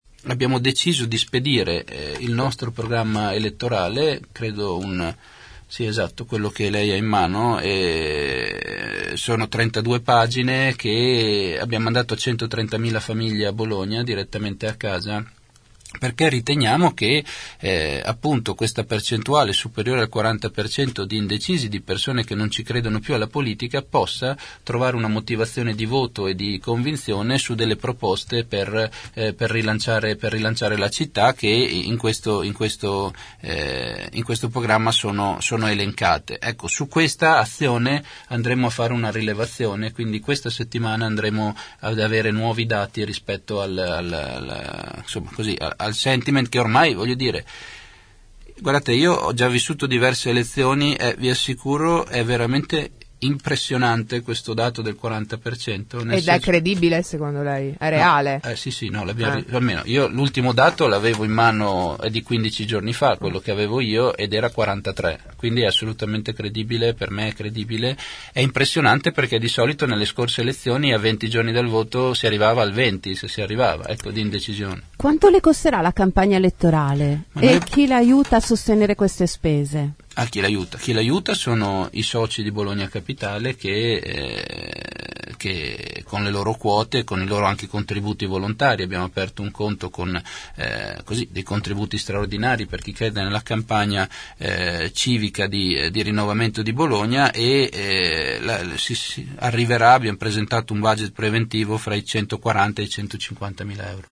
Il candidato civico ne ha parlato nei nostri studi rispondendo alle domande degli ascoltatori.